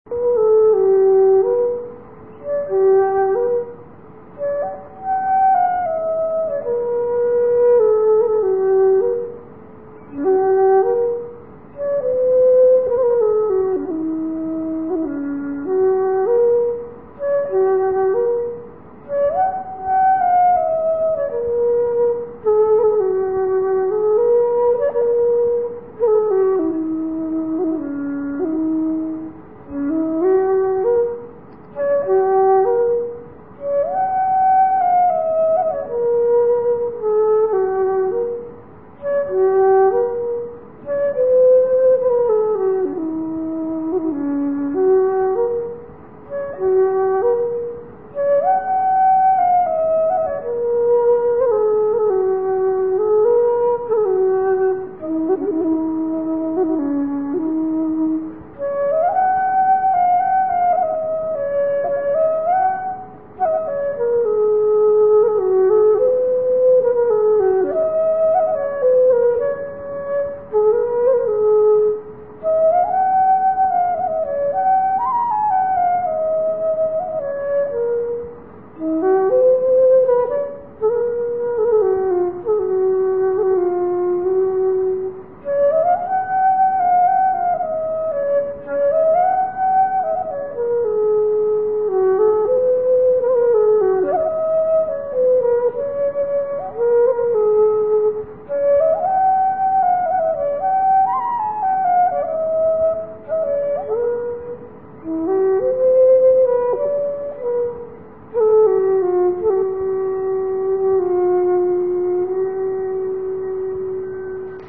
Here are a few short folk melodies I have composed.
Waltzes and Airs